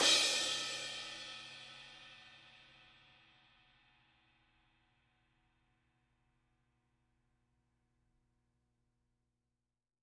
R_B Crash A 01 - Close.wav